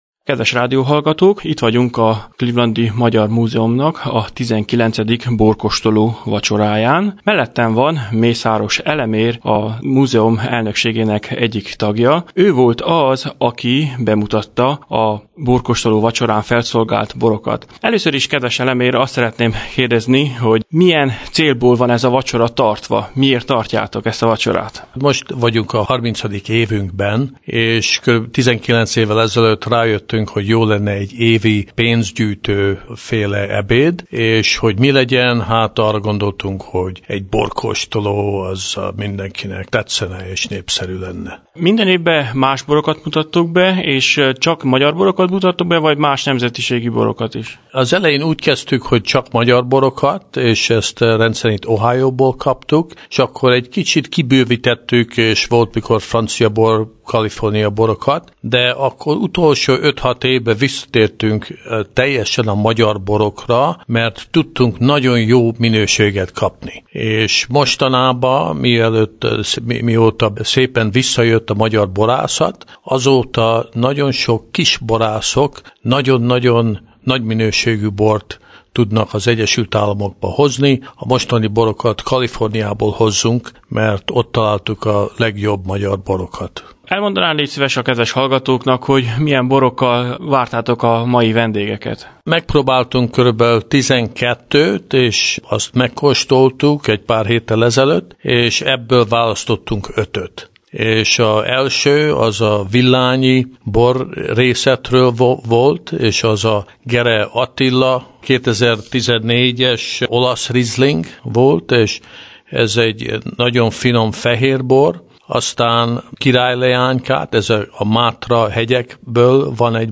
interjút